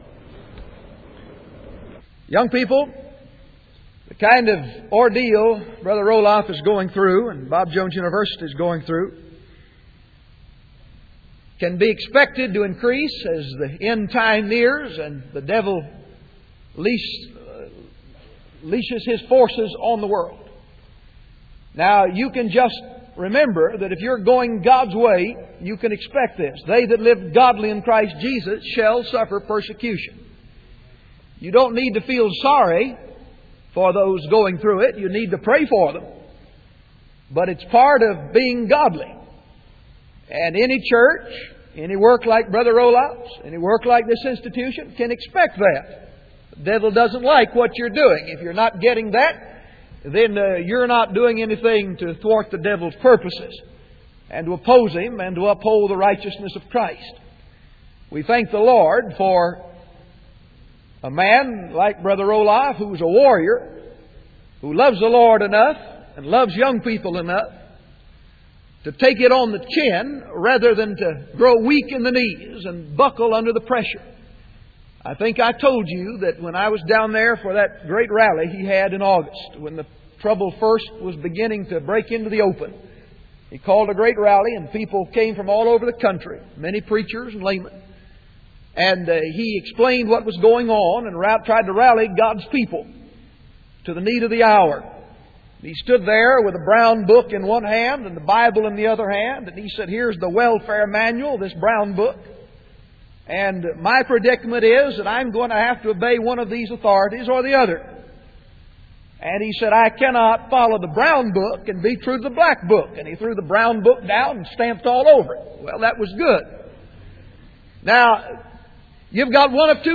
MP3 Audio Sermons